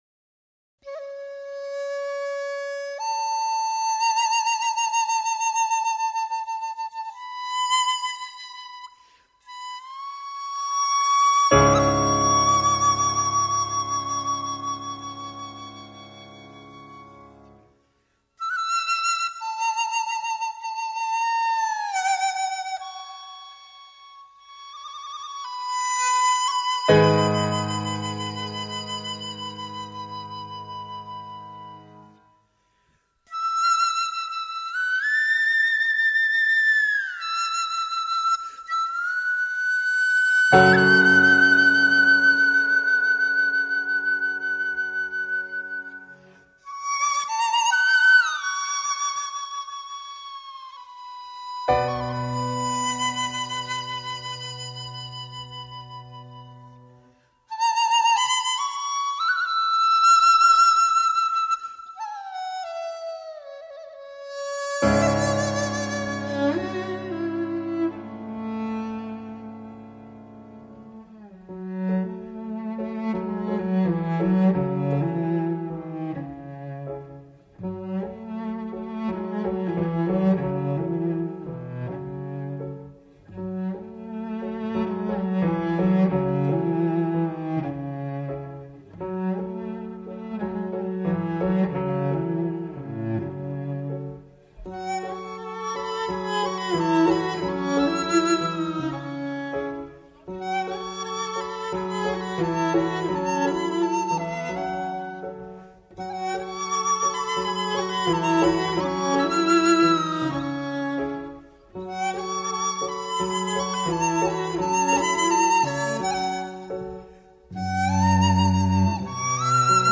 有東方的二胡，有西方的大提琴與鋼琴。 笛聲、蕭聲在專輯中已很難界定是屬於哪裡的，